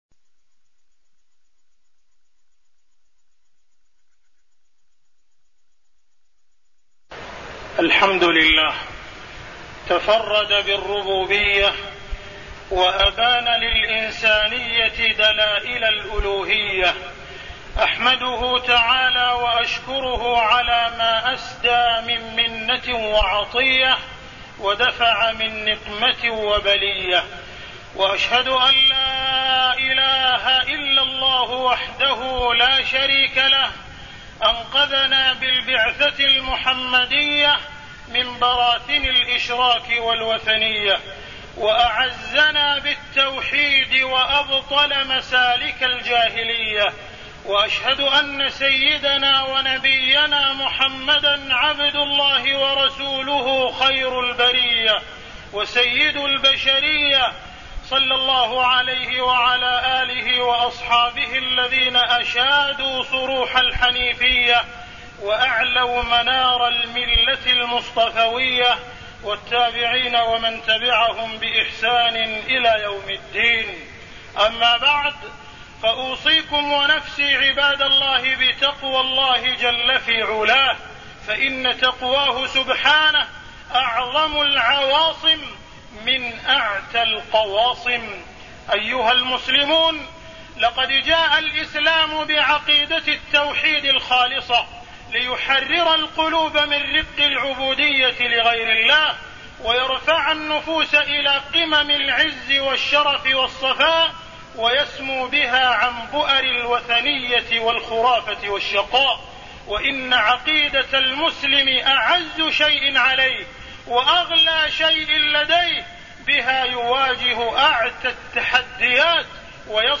تاريخ النشر ١ صفر ١٤٢١ هـ المكان: المسجد الحرام الشيخ: معالي الشيخ أ.د. عبدالرحمن بن عبدالعزيز السديس معالي الشيخ أ.د. عبدالرحمن بن عبدالعزيز السديس مظاهر الشرك والخرافة والتضليل